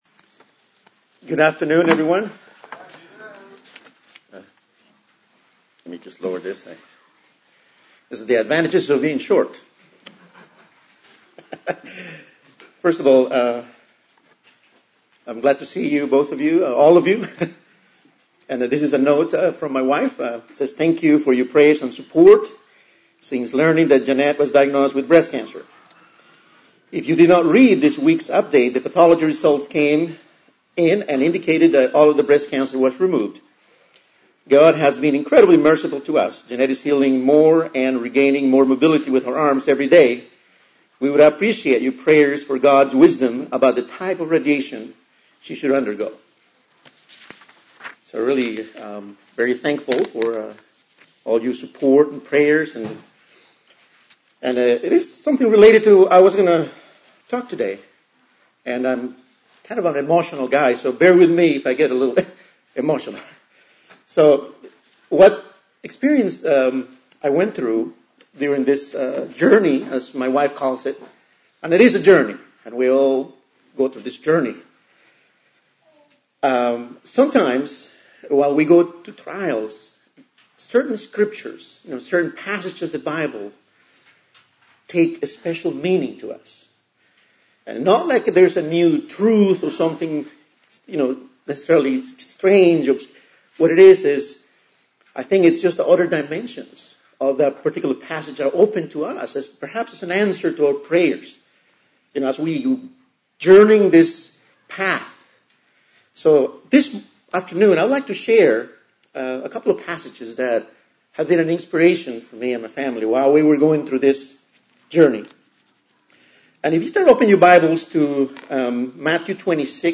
Given in Dallas, TX Fort Worth, TX